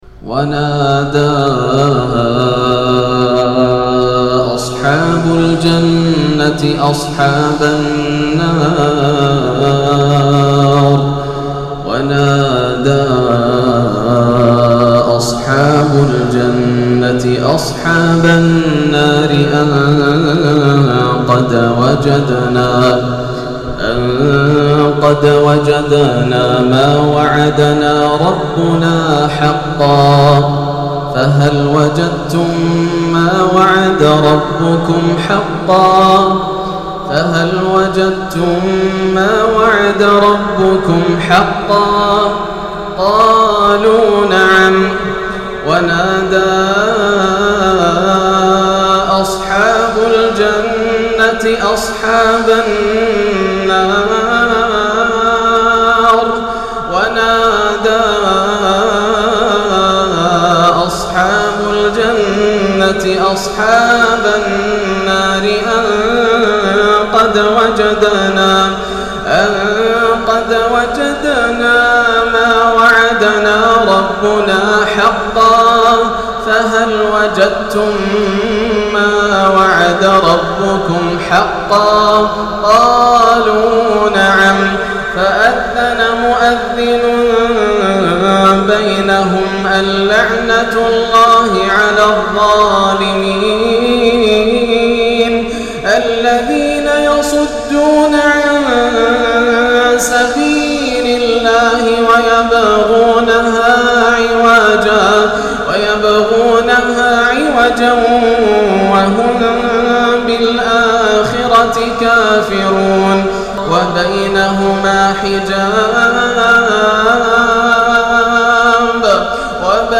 " ونادى أصحاب الجنة أصحاب النار …" تلاوة أسالت المدامع من تراويح ليلة 13 رمضان 1434هـ للشيخ ياسر الدوسري